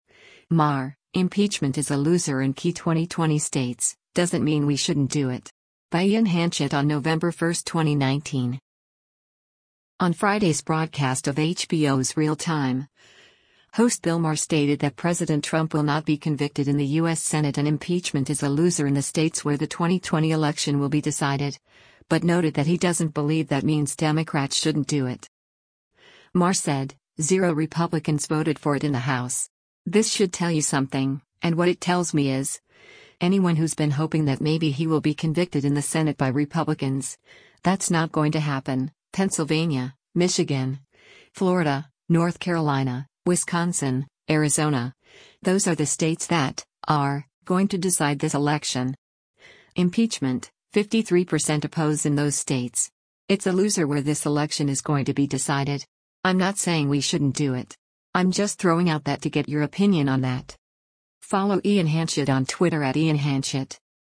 On Friday’s broadcast of HBO’s “Real Time,” host Bill Maher stated that President Trump will not be convicted in the U.S. Senate and impeachment is a “loser” in the states where the 2020 election will be decided, but noted that he doesn’t believe that means Democrats shouldn’t do it.